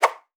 Arrow.wav